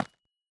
Minecraft Version Minecraft Version 21w07a Latest Release | Latest Snapshot 21w07a / assets / minecraft / sounds / block / calcite / place1.ogg Compare With Compare With Latest Release | Latest Snapshot